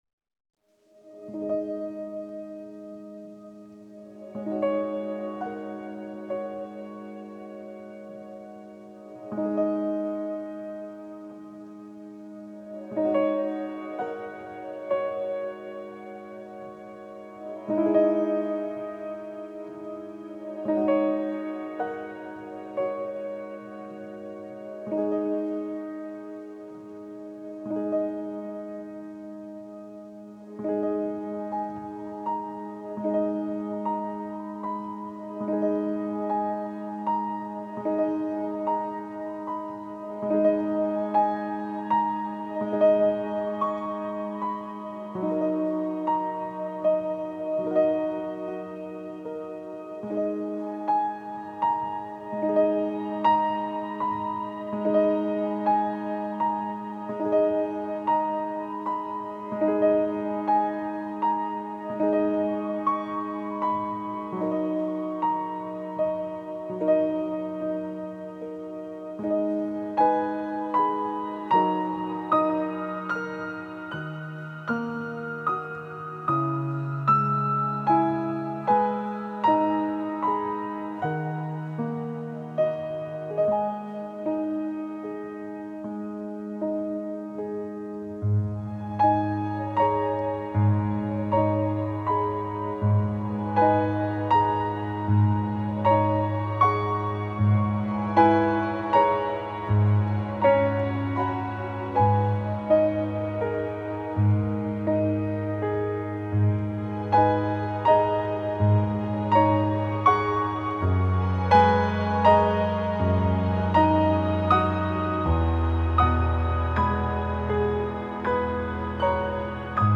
并且回到亲切的钢琴独奏从而表现出一种释然的情怀。